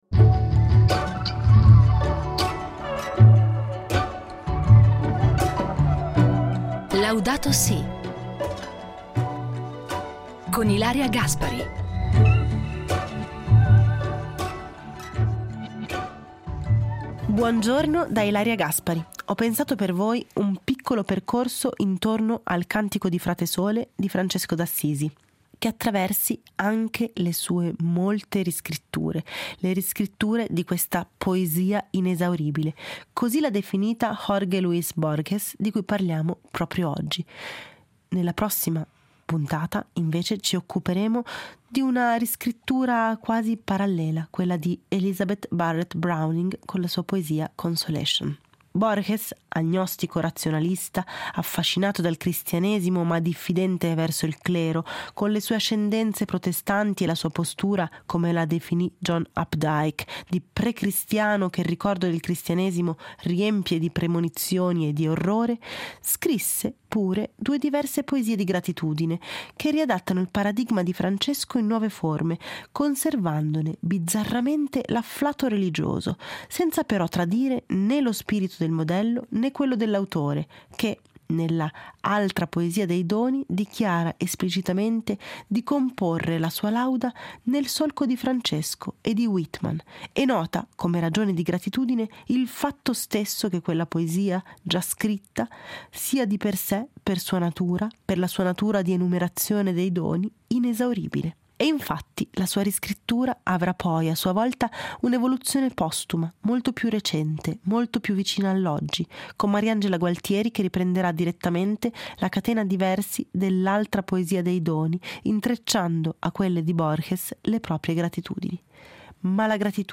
Il Cantico dei cantici letto da Ilaria Gaspari